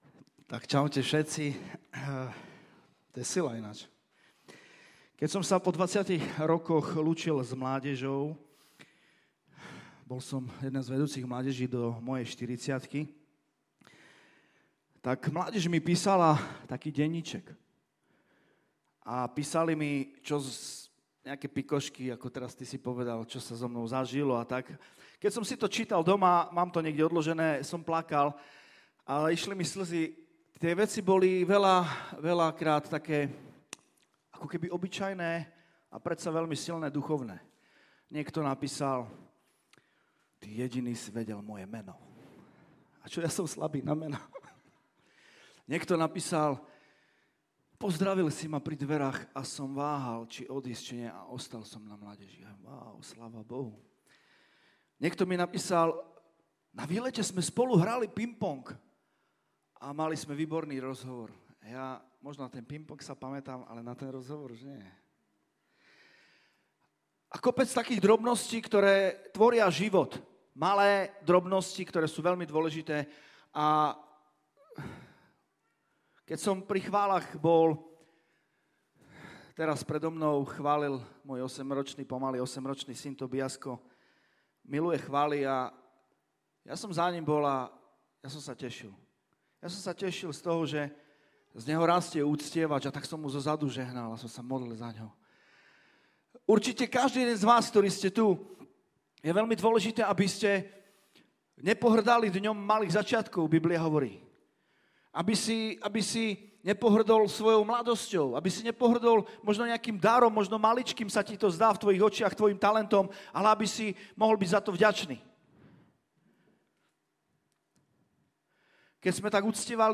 NAJNOVŠIA KÁZEŇ